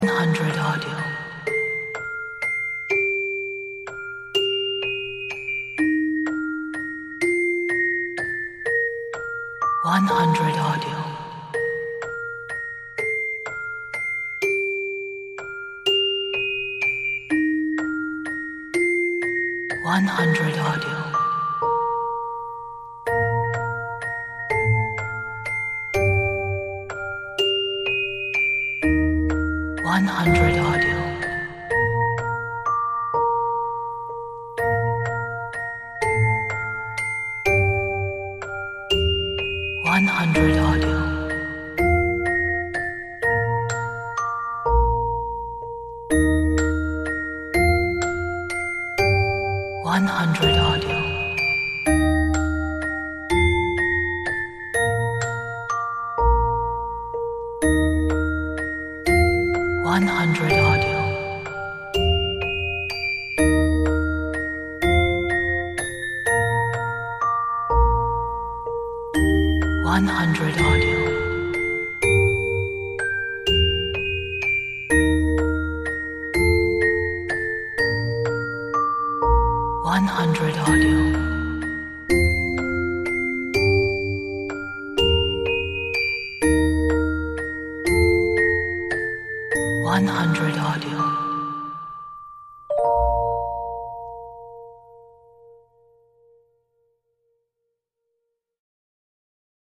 This is a beautiful and soft babies music box melody.